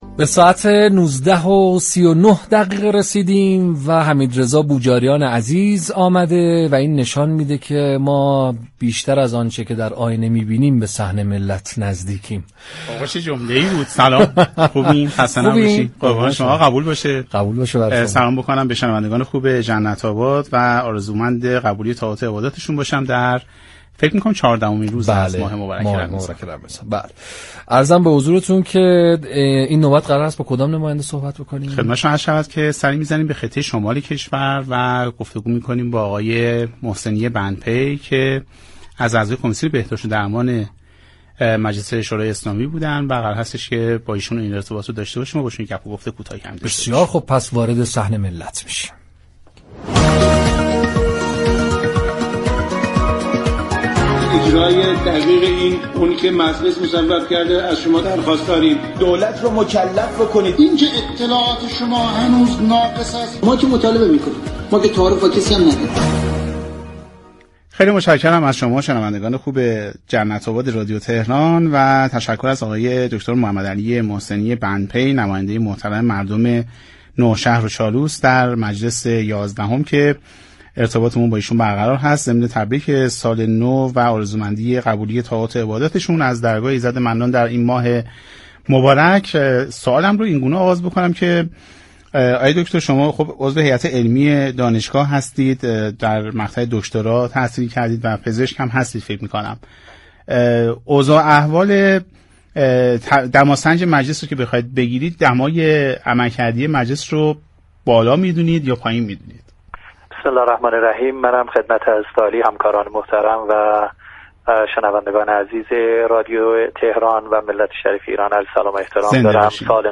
در گفتگو با برنامه «جنت آباد» رادیو تهران